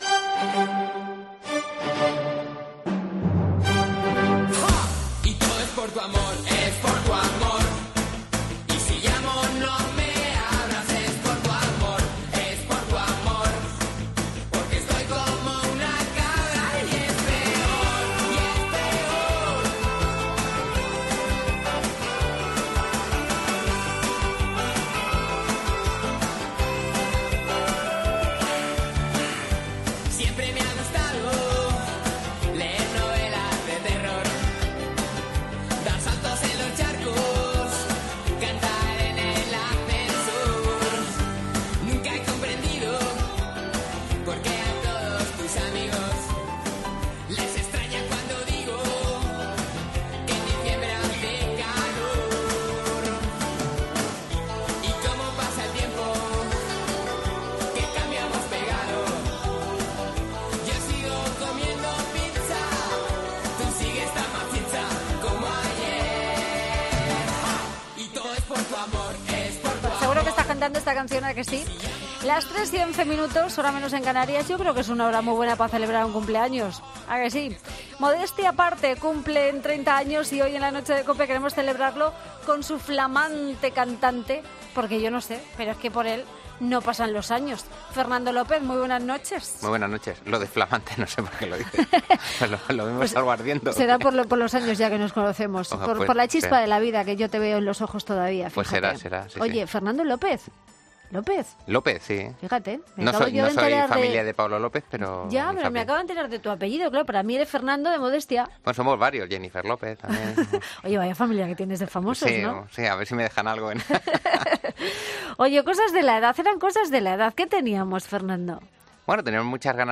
Escucha la entrevista a Fernando López, cantante de Modestia Aparte